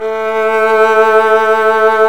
Index of /90_sSampleCDs/Roland L-CD702/VOL-1/STR_Violin 1-3vb/STR_Vln1 % marc